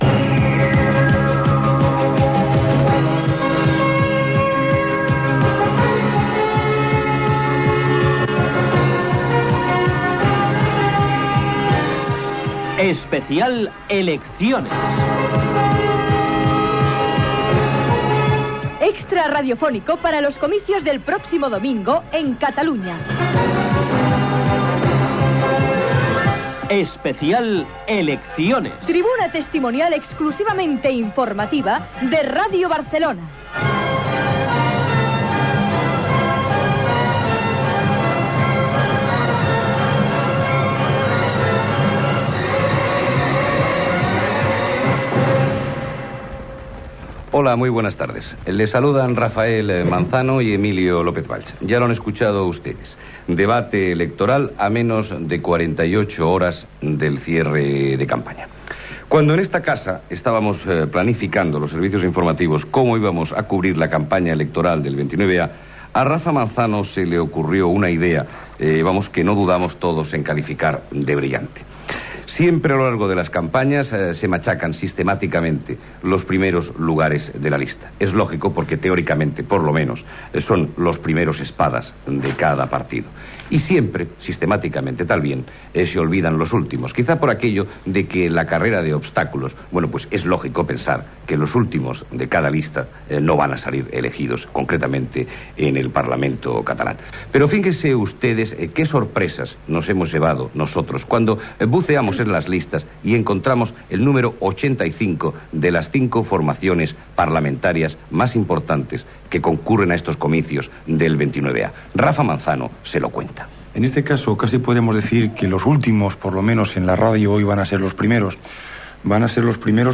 Careta del programa, salutació inicial i presentació del debal electoral amb els últims representants a les llistes dels partits polítics a les eleccions al Parlament de Catalunya
Informatiu